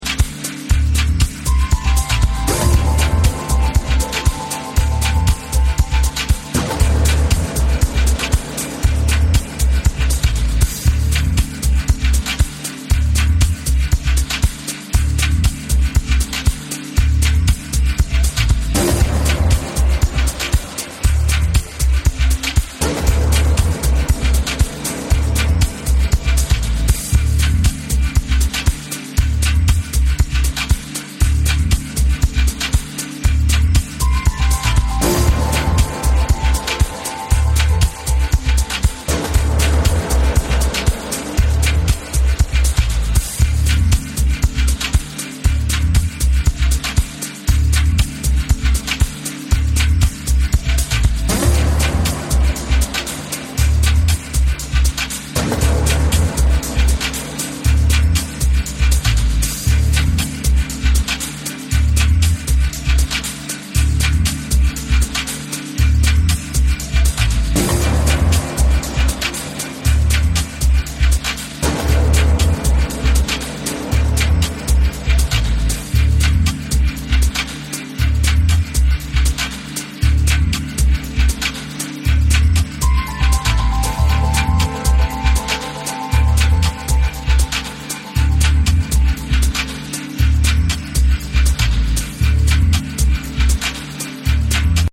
gloriously deep, beautifully produced music